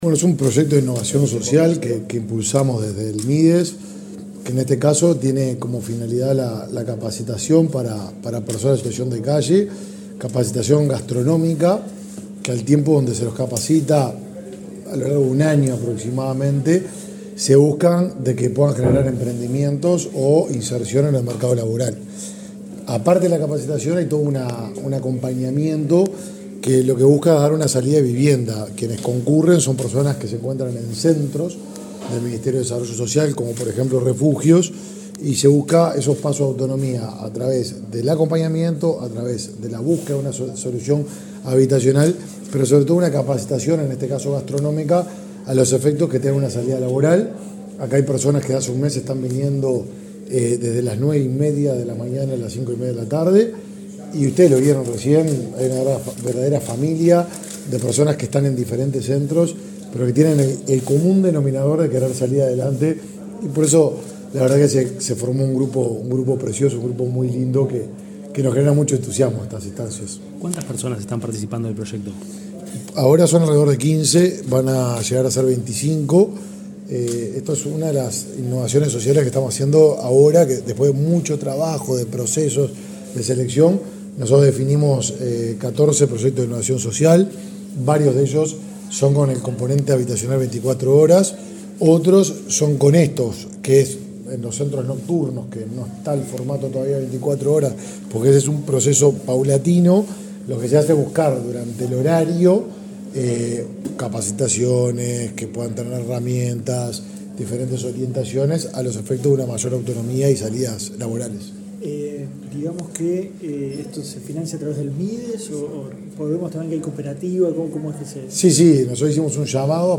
Declaraciones del ministro de Desarrollo Social, Martín Lema
Declaraciones del ministro de Desarrollo Social, Martín Lema 04/09/2023 Compartir Facebook X Copiar enlace WhatsApp LinkedIn El Ministerio de Desarrollo Social (Mides) presentó, este lunes 4 en Montevideo, un proyecto de capacitación gastronómica para contribuir a la inclusión socioeconómica de personas adultas sin hogar. El titular de la cartera, Martín Lema, informó a la prensa sobre las características de la iniciativa.